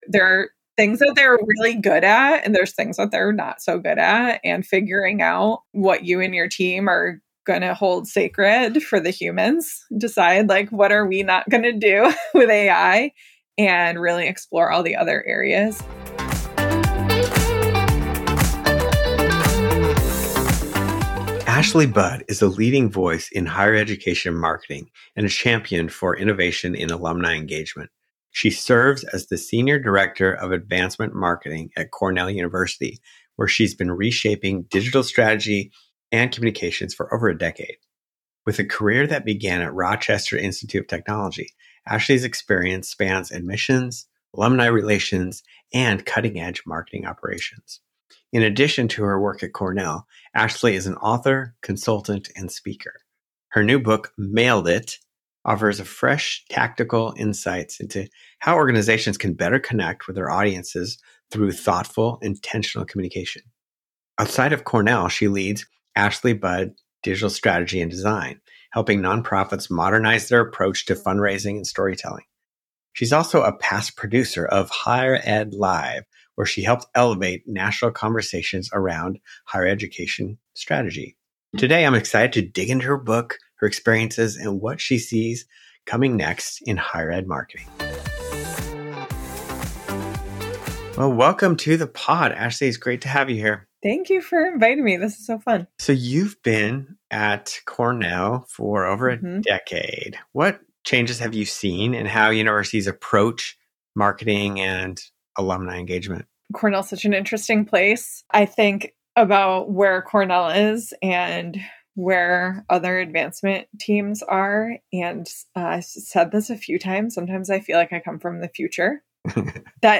Tune in for a conversation packed with practical advice, surprising lessons, and a glimpse into the future of higher ed marketing.